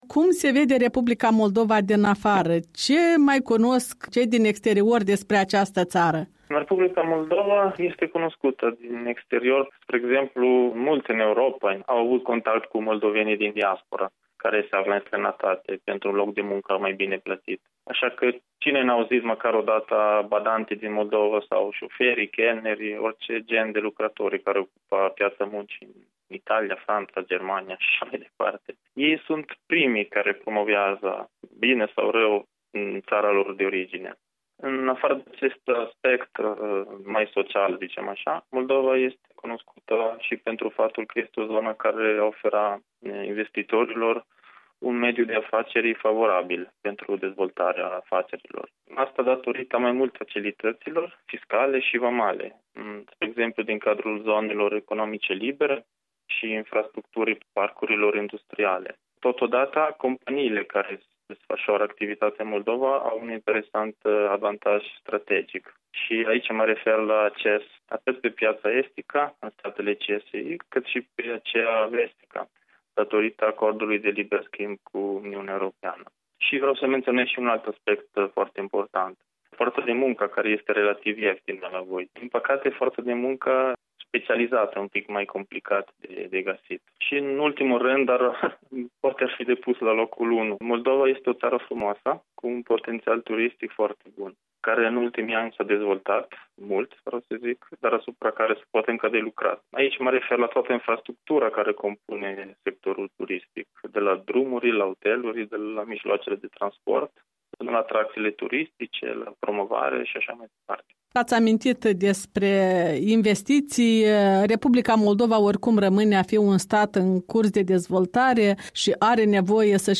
O discuție cu un cetăţean italian care a activat la Camera de Comerţ Moldo-Italiană.